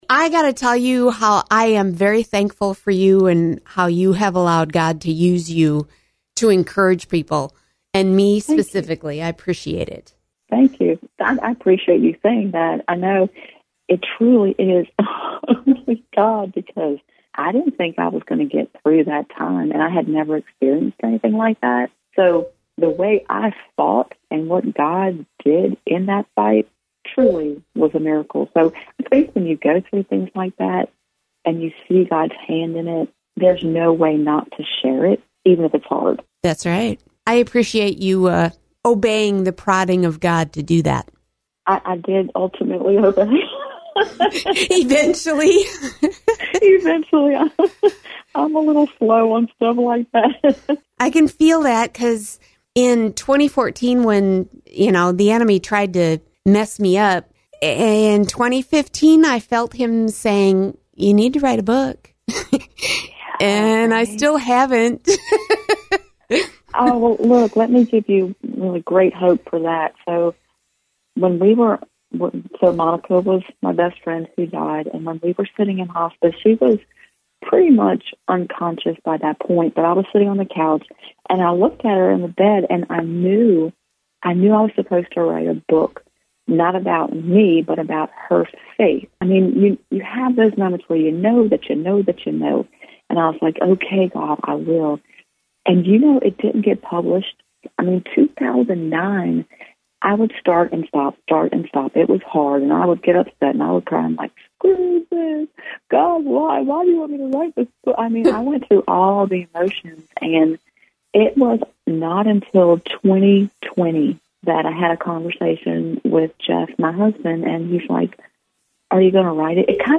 What started out as a book interview became a conversation.